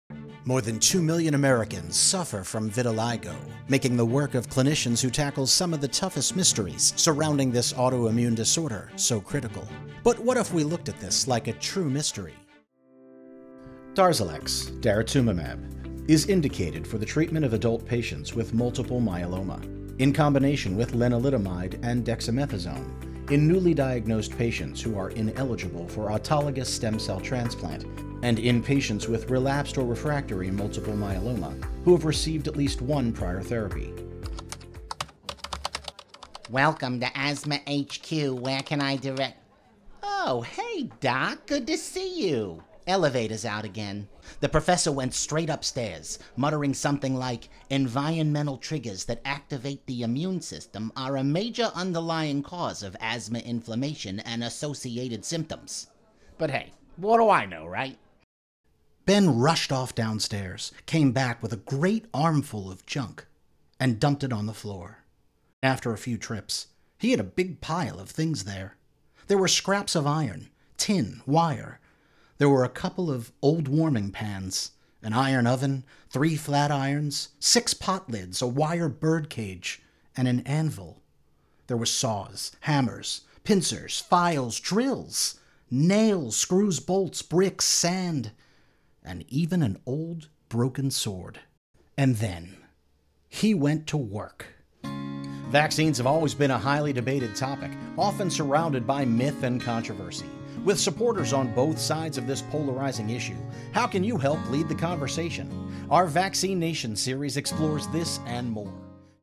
Relatable and easy to listen to male voice, specializing in medical education and difficult terminology.
Cockney, standard British, Philly, Scottish
Young Adult
Middle Aged